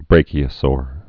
(brākē-ə-sôr, brăkē-)